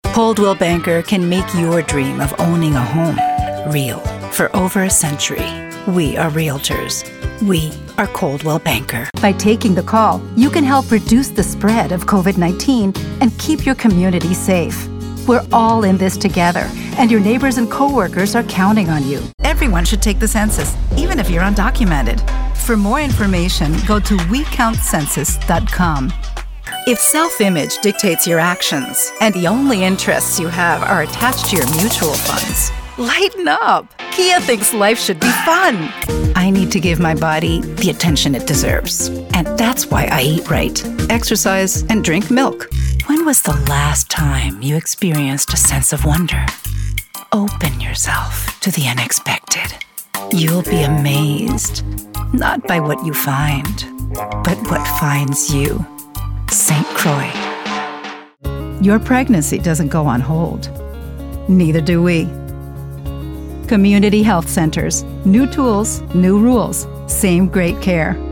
I am a professional native, fully bilingual English/Spanish voiceover talent.
Warm
Friendly
Natural